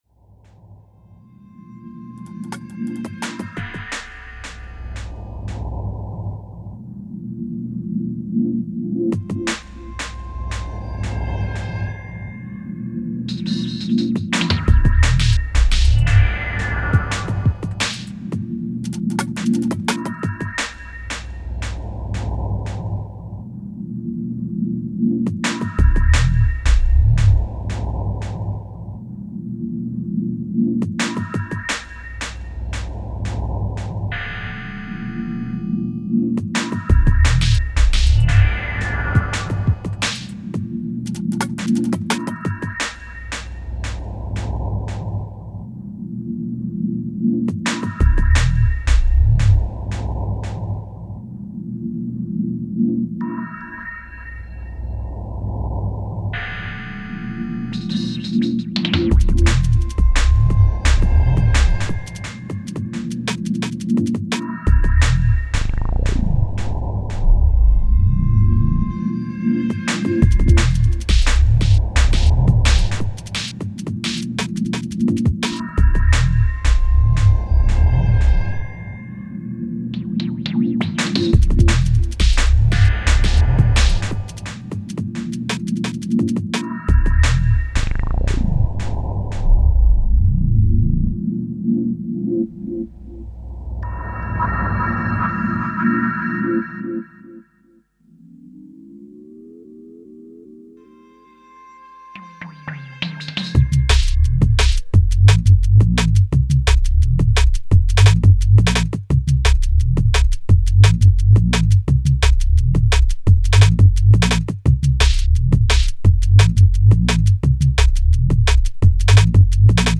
futuristic dark sounds
DNB